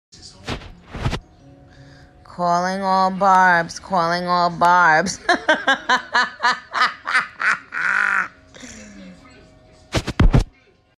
nicki-minaj-calls-out-all-barbz-in-twitters-new-voice-feature-baabmedia.mp3